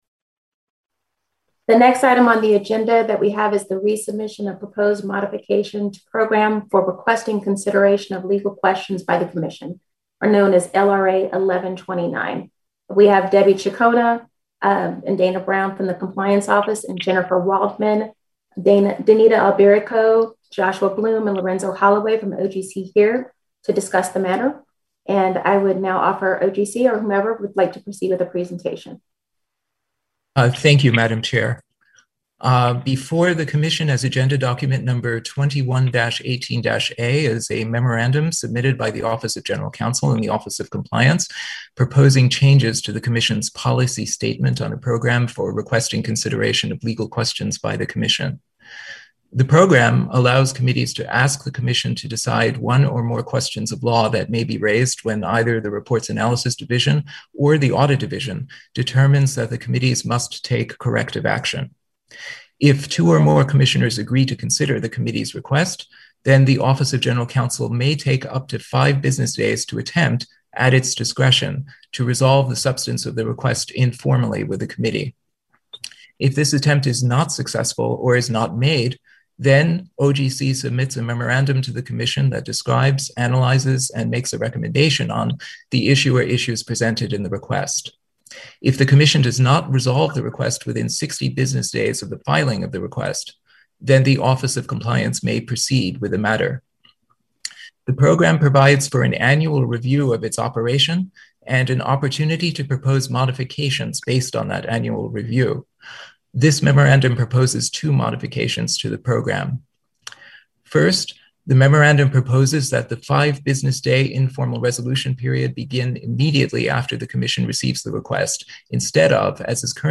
April 8, 2021 open meeting of the Federal Election Commission